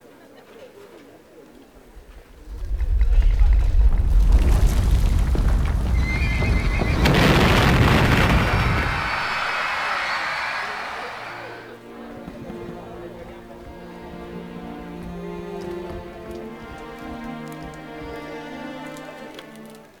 This soundscape narrates the story of Marcus Curtius, tracking the explosions of the chasm opening in the Forum, and the chaos as Roman Citizens struggled to respond. The sound of a horse rearing can be heard, to mark Curtius’ realization and decision to sacrifice himself. The end of the soundscape features quiet and nostalgic Italian music, honoring the heroism of Marcus Curtius in the legend.